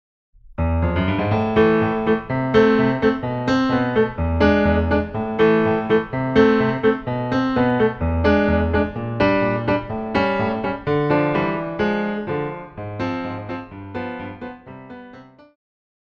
鋼琴
演奏曲
世界音樂
僅伴奏
沒有主奏
沒有節拍器